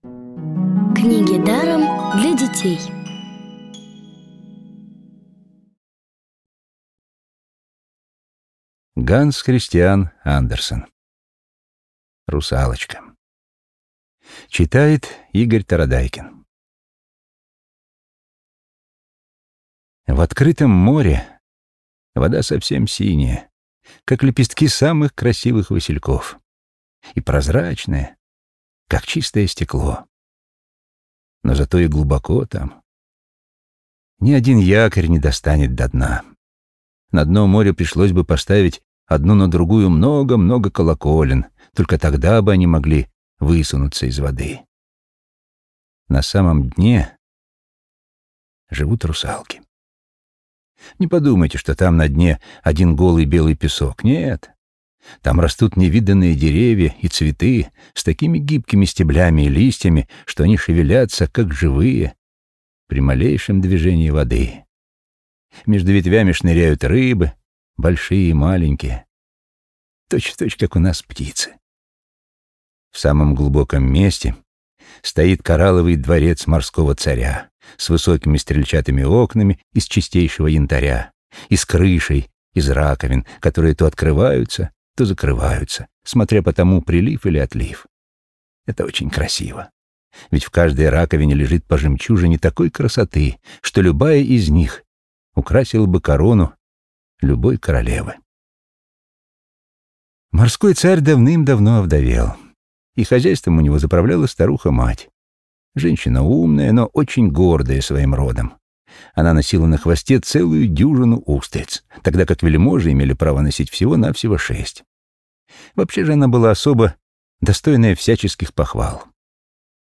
Аудиокниги онлайн – слушайте «Русалочку» в профессиональной озвучке и с качественным звуком.